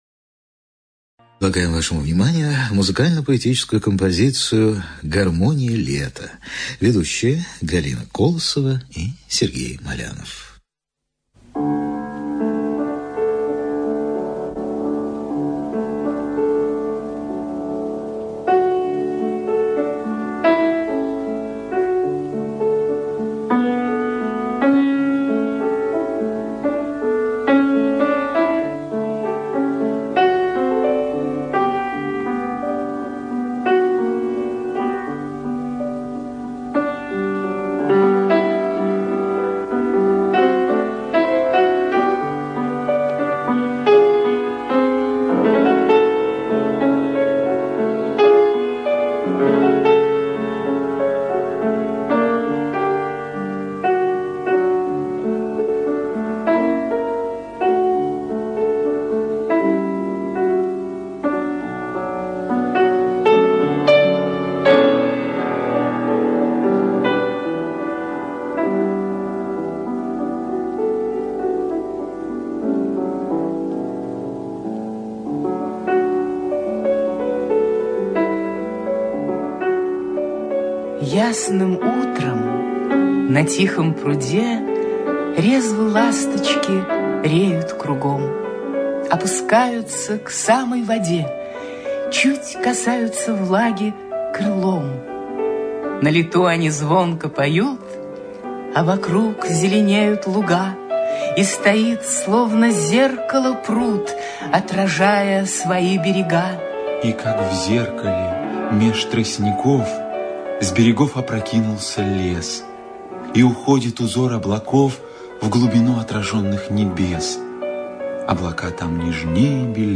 НазваниеГармония лета. Музыкально-литературная композиция